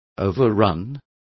Complete with pronunciation of the translation of overran.